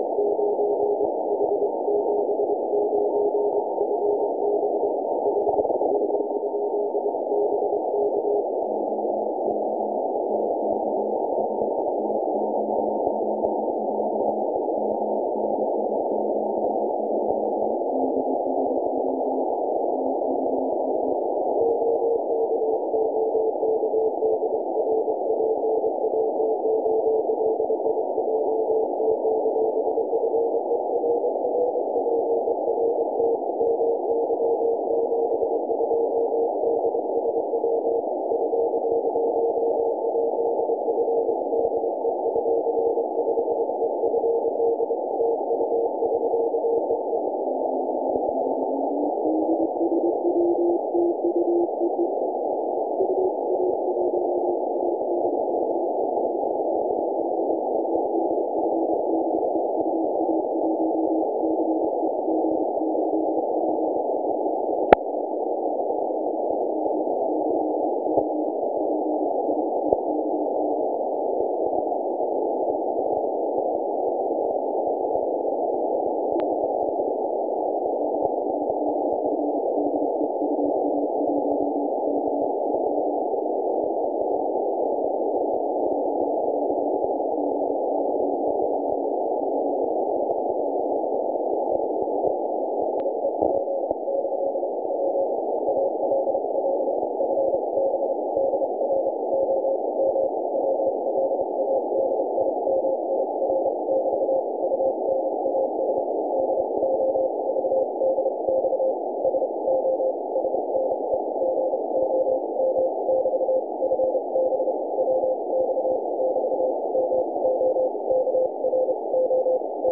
Поработал сегодня в рандеву (вт, чт 12.00 МСК 14060 кГц) из лесов- полей.